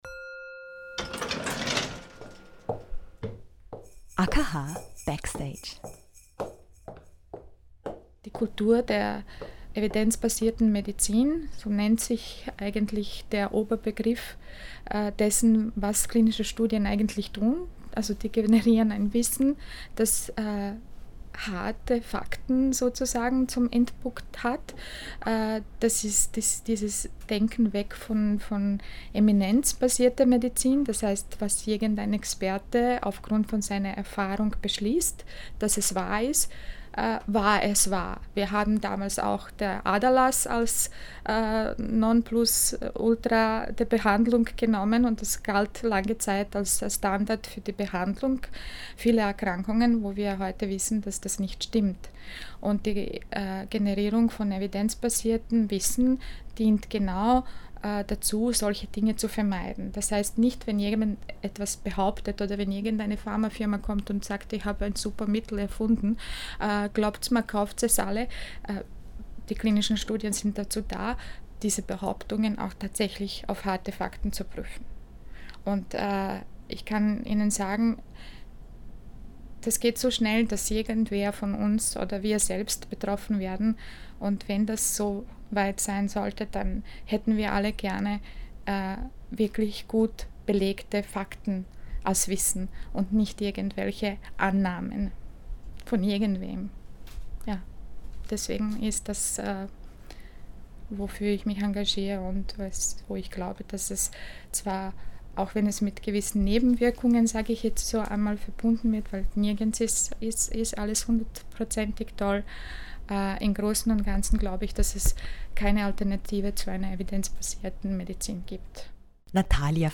Sie spricht über die Vorteile einer Teilnahme an klinischen Studien und bricht mit dem Versuchskaninchen-Image. Wie ein Tagesablauf einer Study Nurse aussieht und wann eine Studie abgebrochen wird und warum – darüber und mehr erfahren Sie im Interview: